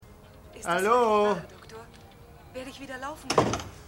Stimme TV-Sprecher